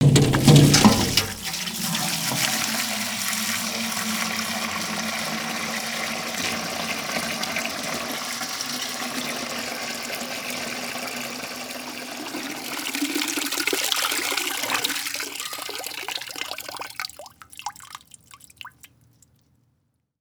added bucket filling sounds
bucket.wav